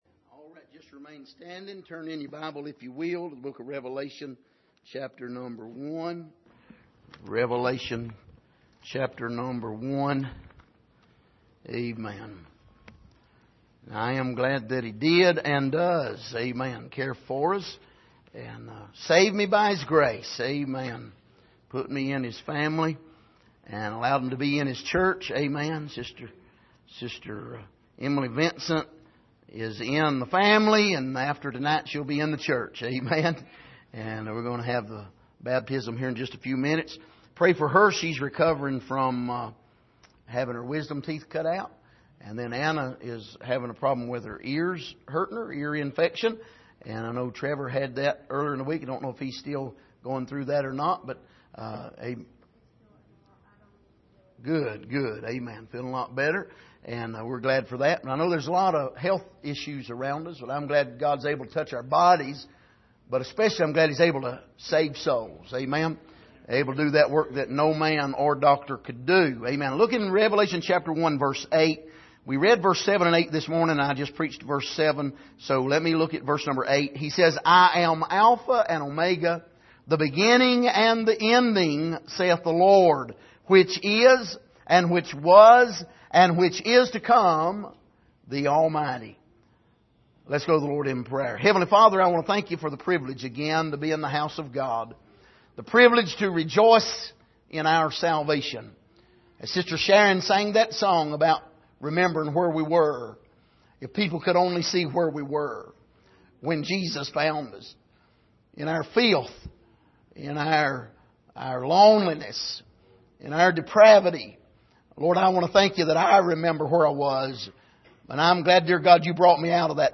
Passage: Revelation 1:8 Service: Sunday Evening